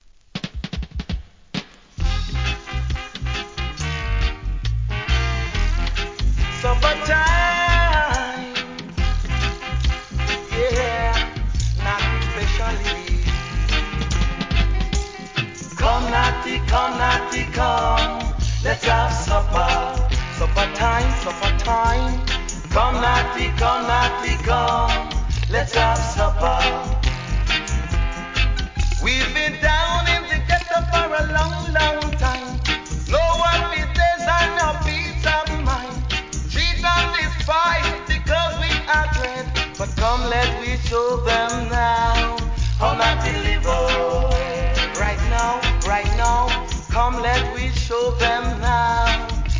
1. REGGAE
後半DUB接続!!